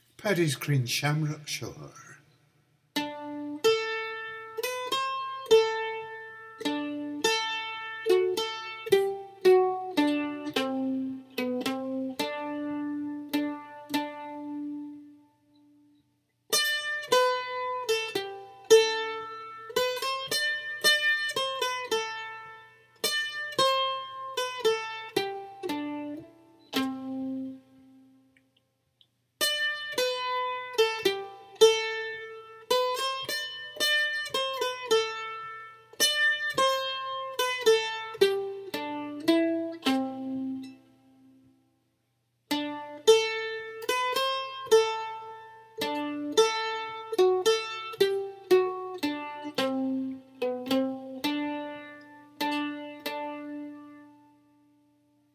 Music Shamrock Shore [STD] Standard notation [TAB] Mandolin tab [MP3] MP3 (mandolin)